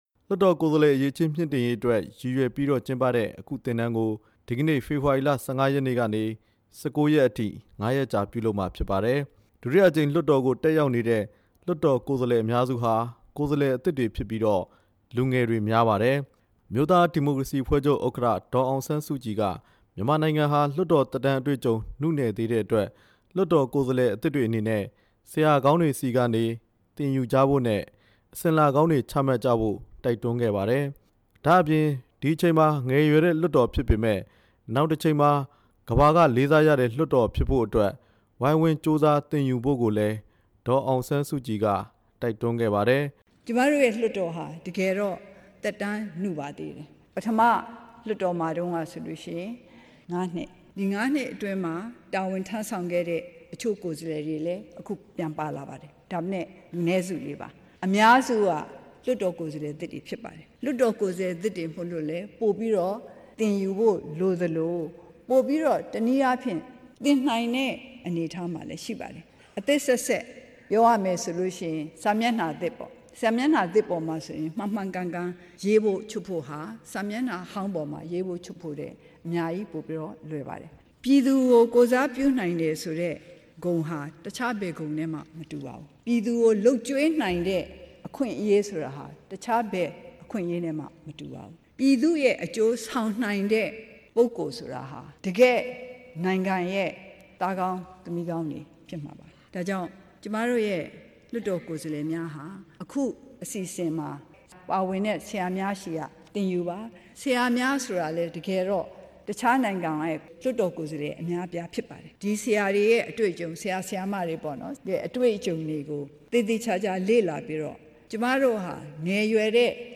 ဒုတိယအကြိမ်လွှတ်တော်မှာ တက်ရောက်နေတဲ့ ကိုယ်စားလှယ်အသစ်တွေအတွက် လွှတ်တော်ဆိုင်ရာလုပ်ငန်းတွေ ရှင်းလင်းတင်ပြတဲ့ သင်တန်းကို ဒီနေ့ ပြည်ထောင်စု လွှတ်တော် သဘင်ခန်းမဆောင်မှာ စတင်ကျင်းခဲ့ပါတယ်။ လွှတ်တော်နှစ်ရပ် ဥက္ကဌတွေနဲ့ အမျိုးသားဒီမိုကရေစီအဖွဲ့ချုပ် ဥက္ကဌ ဒေါ်အောင်ဆန်း စုကြည် တို့က အဖွင့်မိန့်ခွန်းပြောကြားခဲ့ပါတယ်။